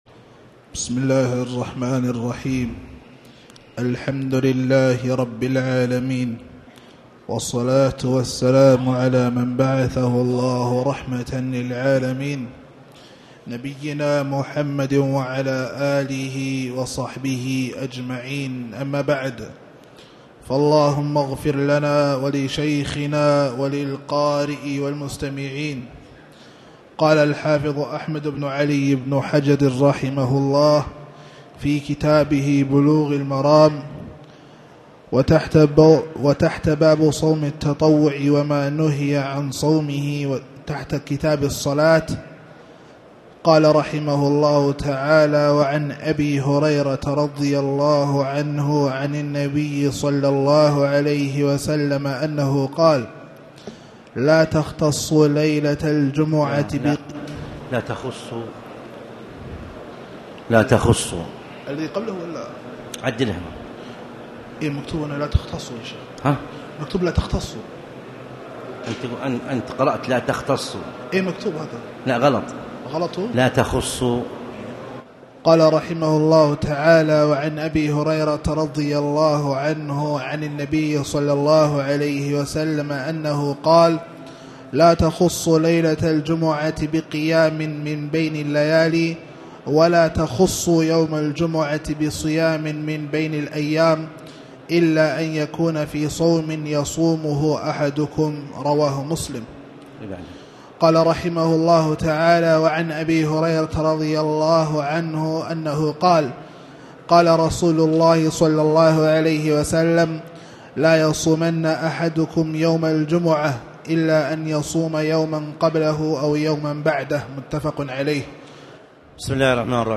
تاريخ النشر ٢٣ شعبان ١٤٣٨ هـ المكان: المسجد الحرام الشيخ